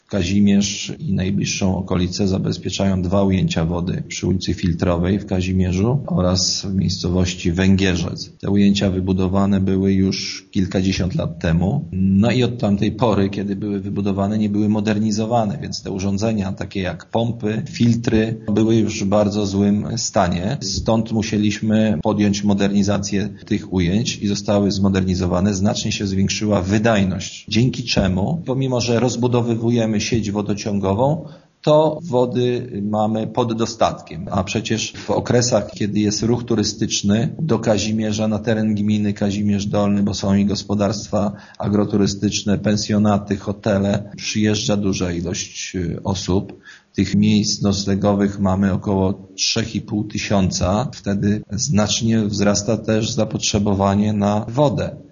Burmistrz Dunia tłumaczy, że w związku z rozbudową wodociągu samorząd musiał też poprawić wydajność ujęć wody: